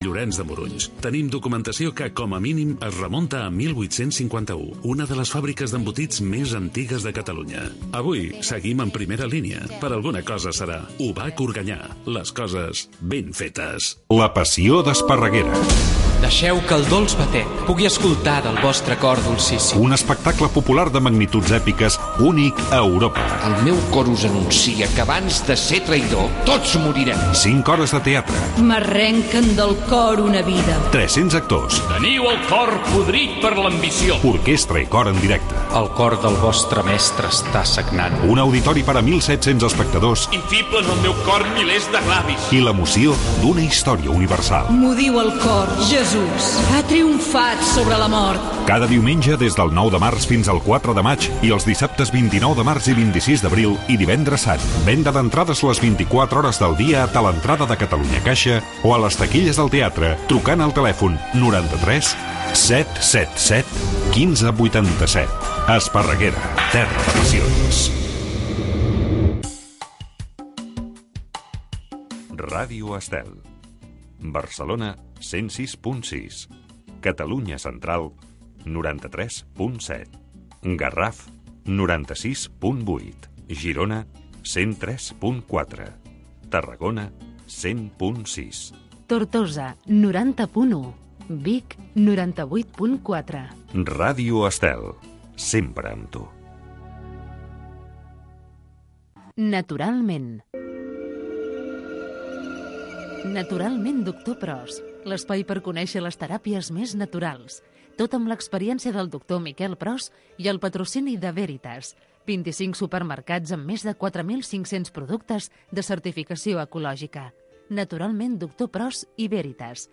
ens presenta un pacient que ens explica el seu cas en primera persona.